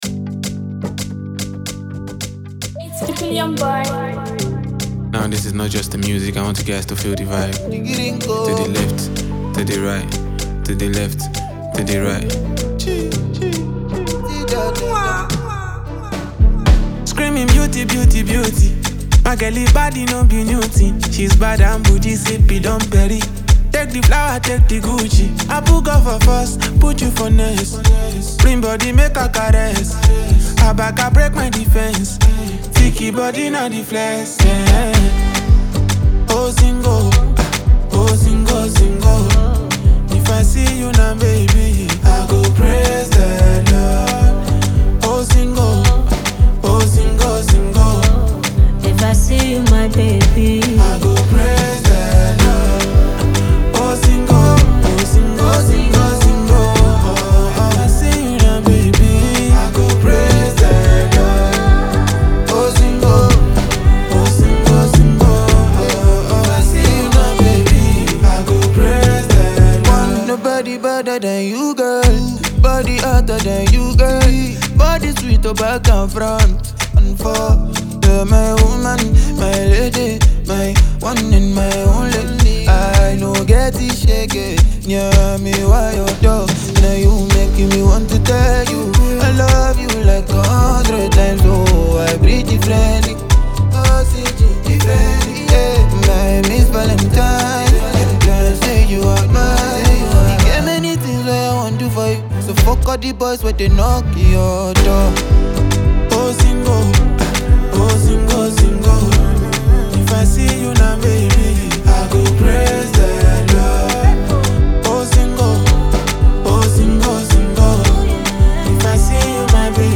blending contemporary African rhythms with melodic vocals.